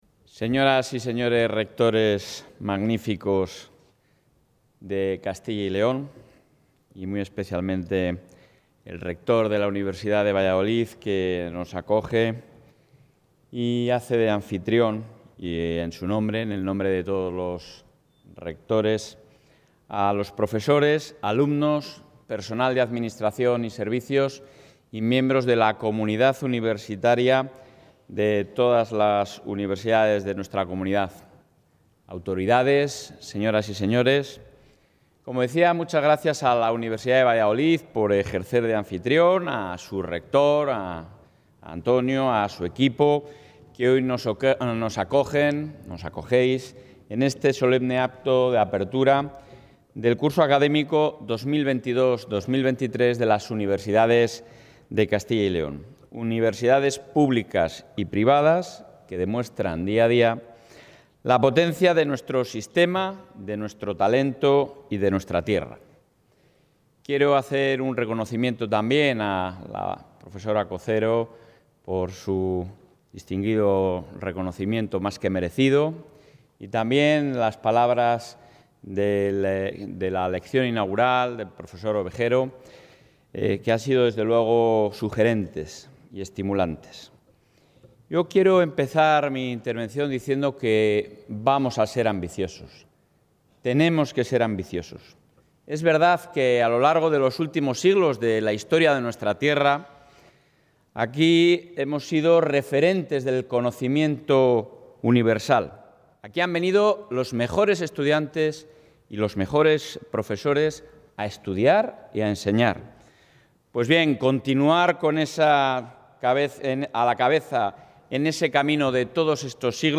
Audio presidente.
En el solemne acto de inauguración de apertura del Curso Académico 2022-2023 en las Universidad de Castilla y León celebrado esta mañana en Valladolid, el presidente autonómico, Alfonso Fernández Mañueco, ha asegurado que está decidido a que ésta sea la legislatura del talento en las universidades de Castilla y León. Para ello, la Junta bajará las tasas y subirá las becas.